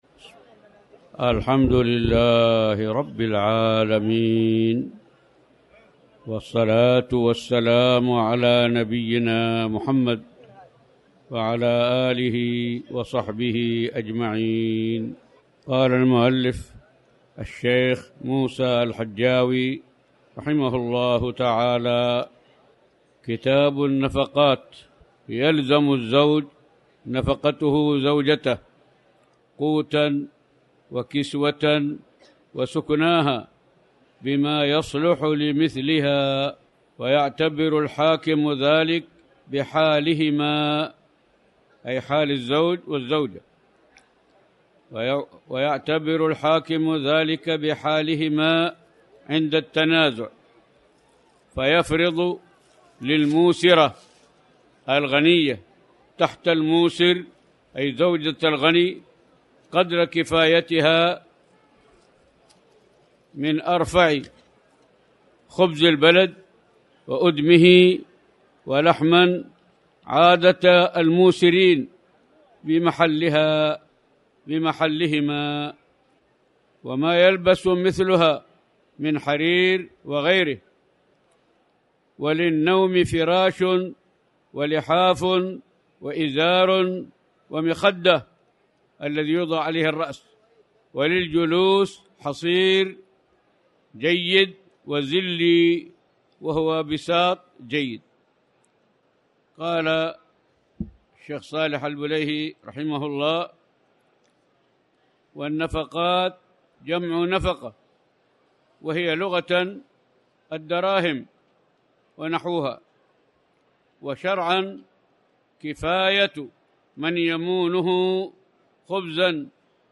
تاريخ النشر ٢٧ رمضان ١٤٣٩ هـ المكان: المسجد الحرام الشيخ